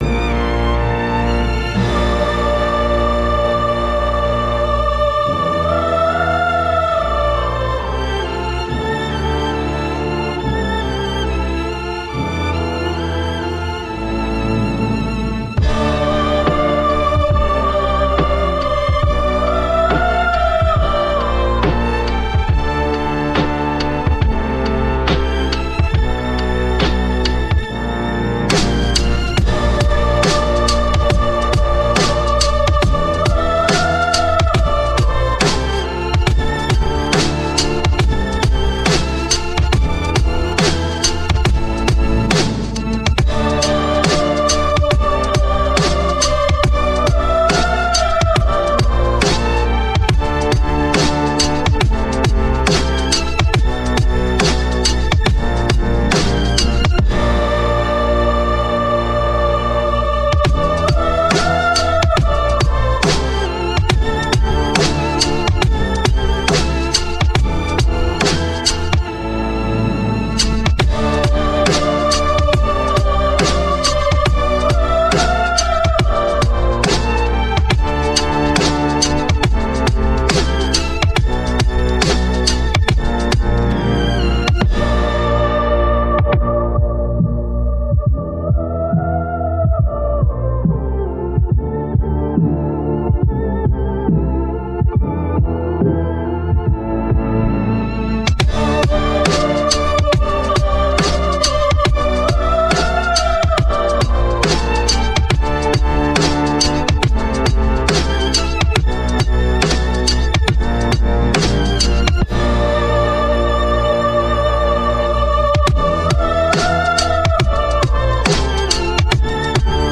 Epic Classical x Heavy Beat (No Copyright)